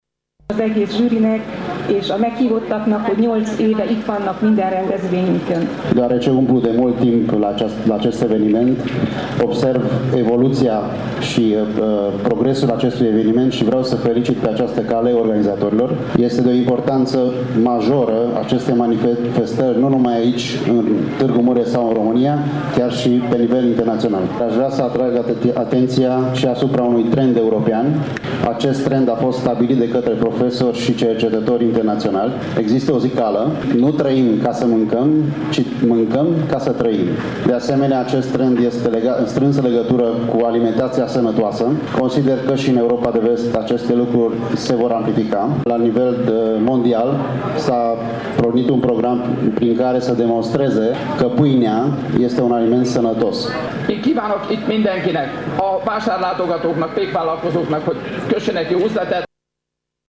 în deschiderea Expoziţiei Internaţionale GastroPan 2016 de la Tîrgu Mureș.